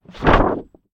Звуки плаката